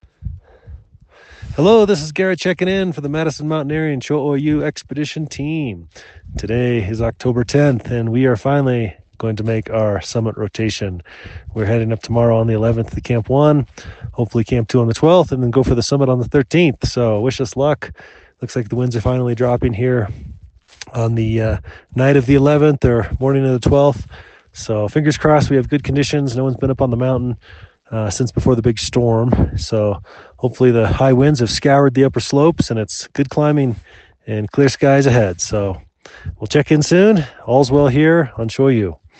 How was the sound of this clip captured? checks in with this dispatch from Cho Oyu